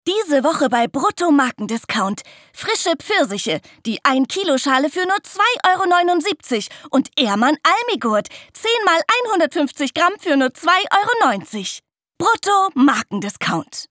Deutsche Sprecherin/Schauspielerin, Werbung, Voiceover, Hörbuch/Hörspiel, Off- Sprecherin, Werbespots, junge Stimme, sonnig, sinnlich, smart Skills: Gesang, Teenagerstimmen, Comedy
Kein Dialekt
Sprechprobe: Werbung (Muttersprache):